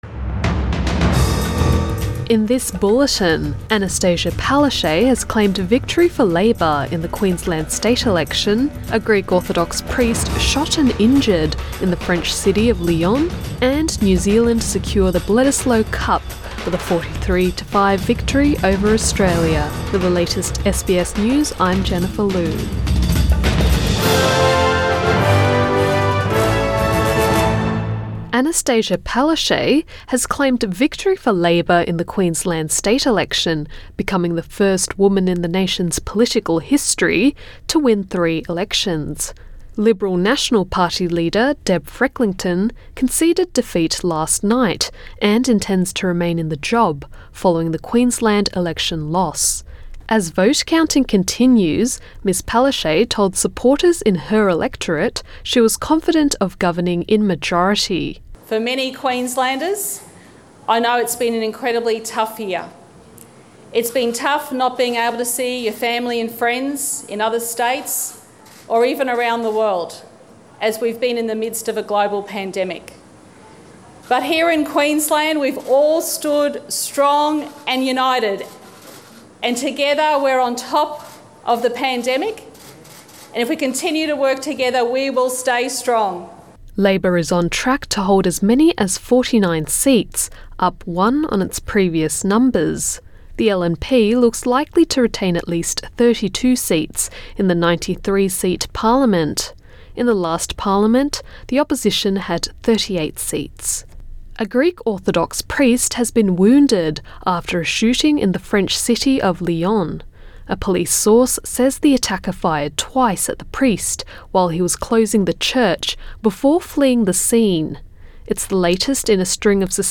AM bulletin 1 November 2020